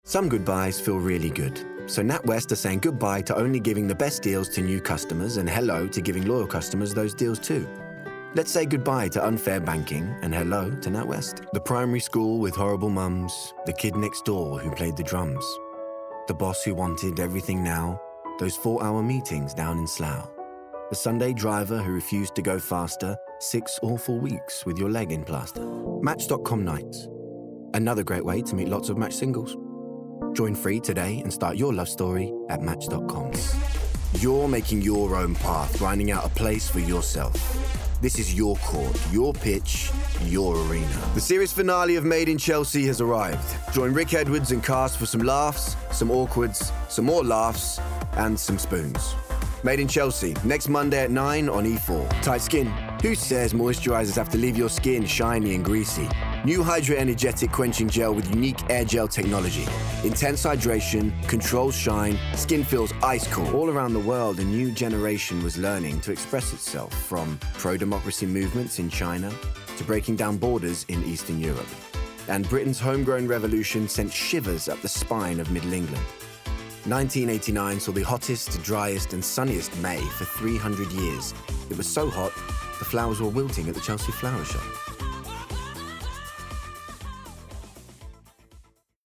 Commercial Showreel
London, RP ('Received Pronunciation'), Straight
Showreel, Cool, Commercial, Edgy